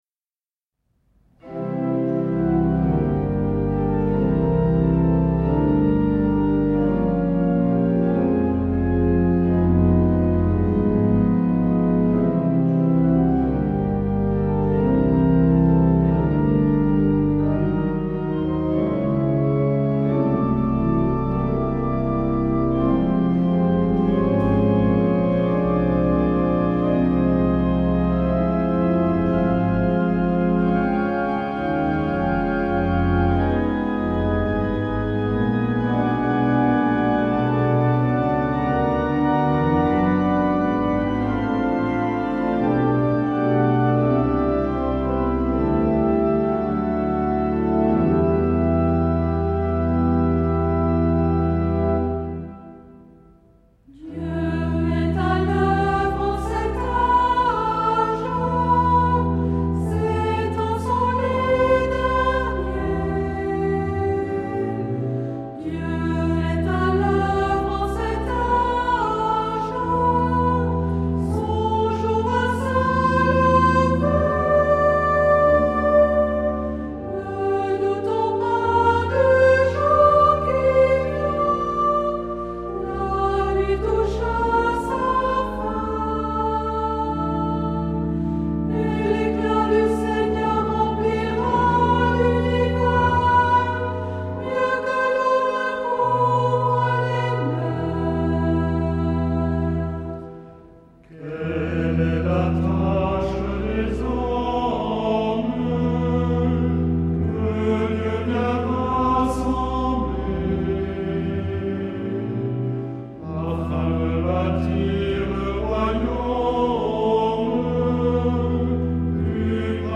Genre-Style-Forme : Hymne (sacré)
Caractère de la pièce : recueilli ; calme ; lent
Type de choeur :  (1 voix unisson )
Instrumentation : Orgue  (1 partie(s) instrumentale(s))
Tonalité : mi mineur